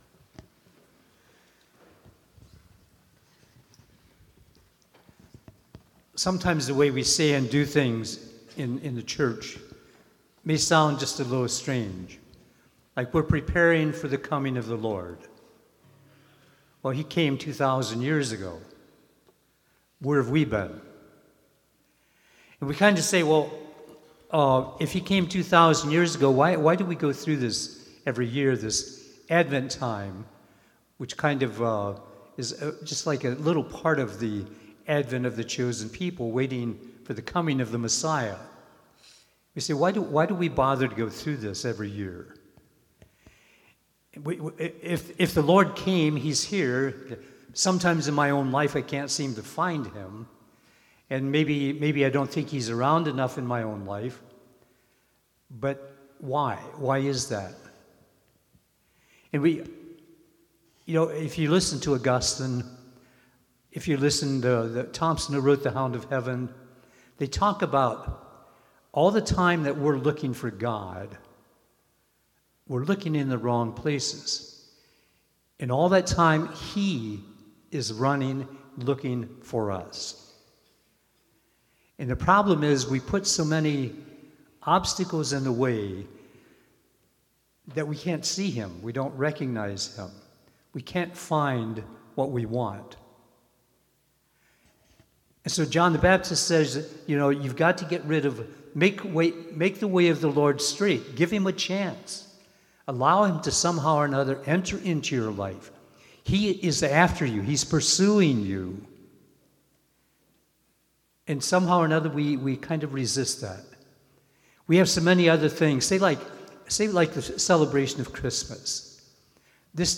Cycle B